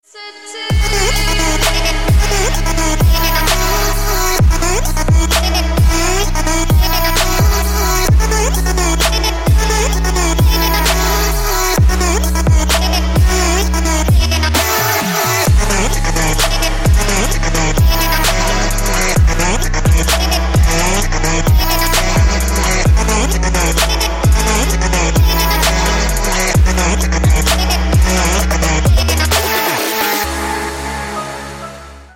Trap рингтоны
Трэп отбивка на телефон